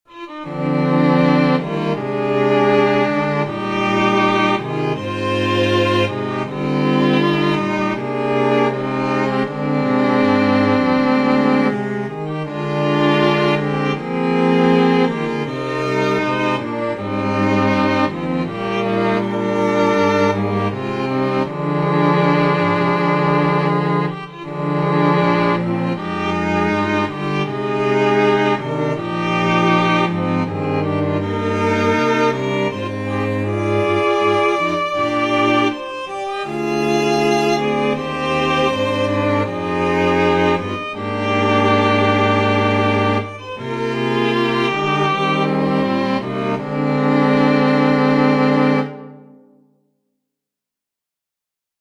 Scellan_anthem.mp3 (file size: 886 KB, MIME type: audio/mpeg)
contribs)slowed down + tweaks